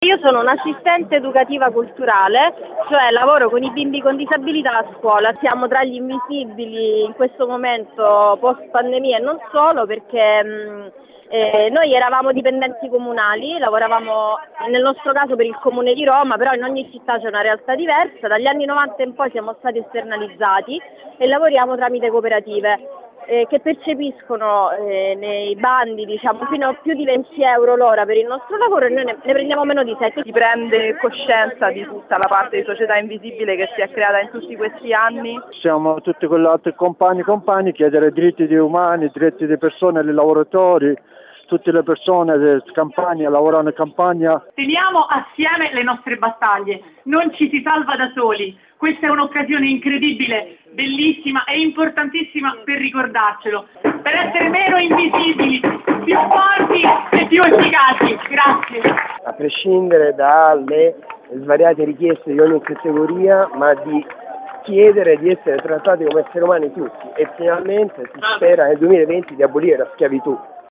A Roma, piazza san Giovanni, oggi c’è stata la manifestazione degli “Stati Popolari”.
MONTAGGIO-VOCI-19.30-stati-popolari.mp3